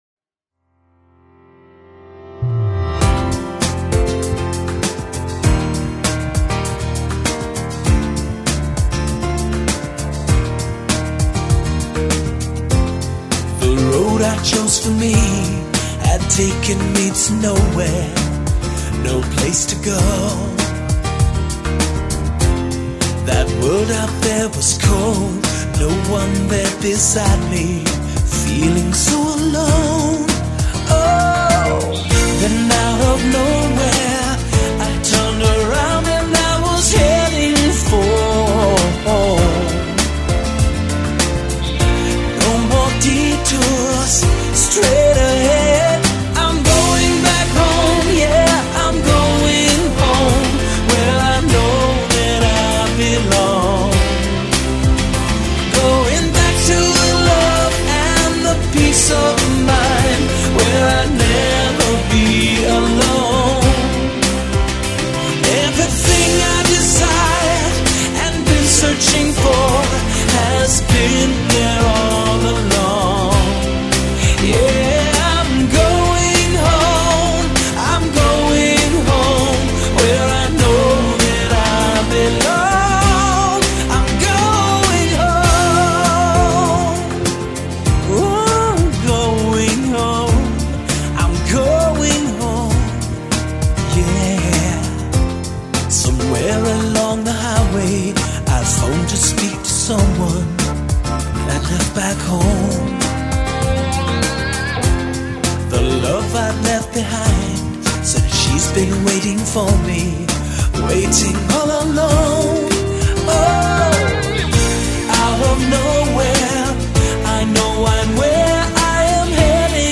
uptempo pop-rock song